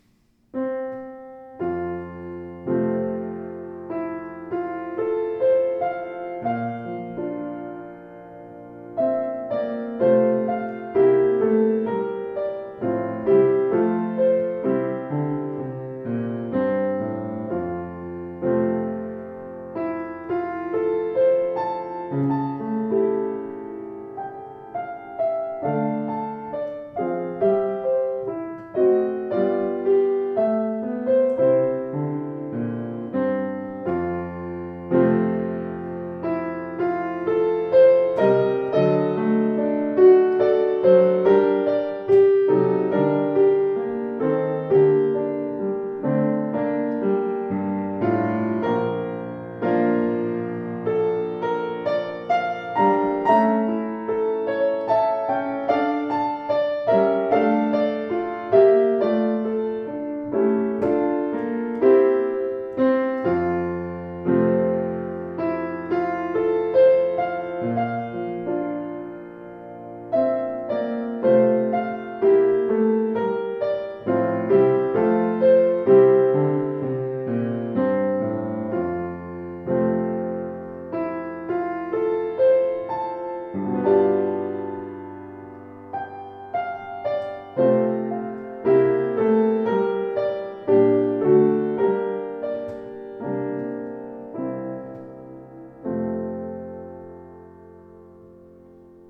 Klavier Yamaha YU10 schwarz
Schwarz poliert mit ausdruckstarkem Klang und klaren Bässen (121cm Bauhöhe wie das bekannte Modell Yamaha U1)